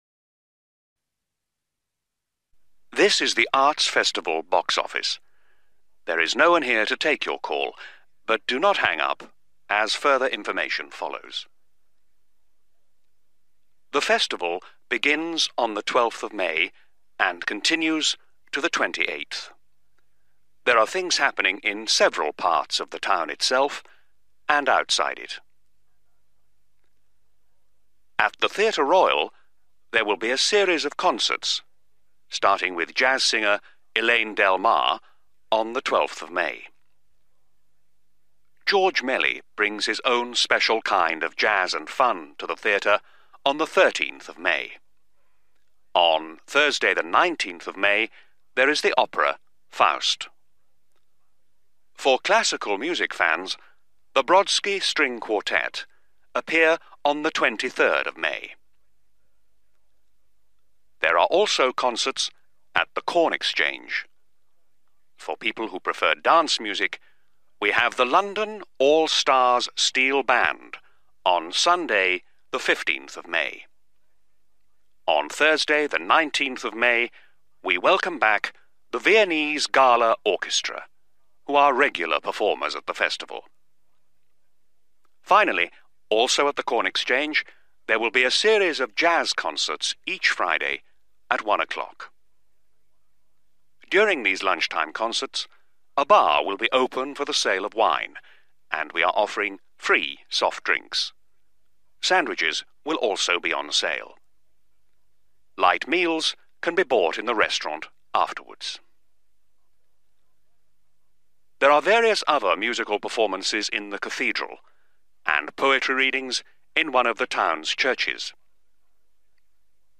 You will hear a recorded message about an arts festival.